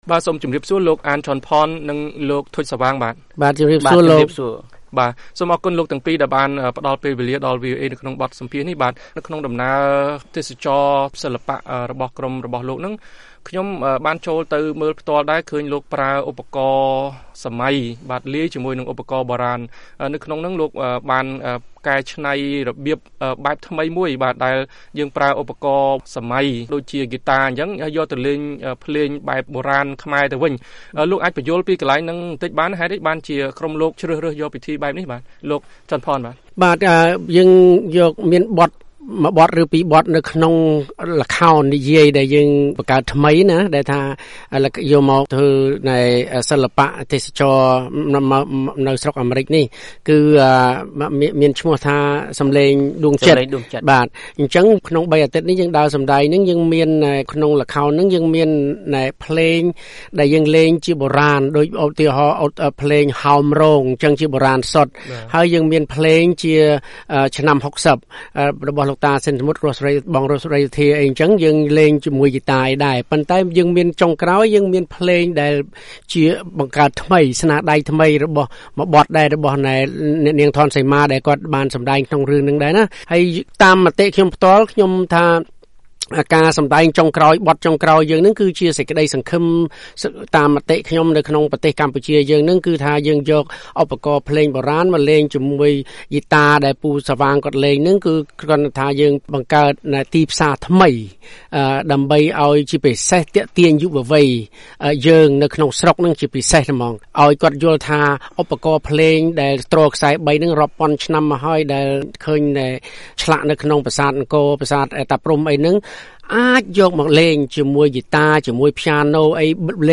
បទសម្ភាសន៍VOA៖សិល្បៈខ្មែរអមតៈ ប្រឹងពង្រីកទីផ្សារសម្រាប់សិល្បករបុរាណខ្មែរ ដោយបង្កើតស្នាដៃថ្មីៗ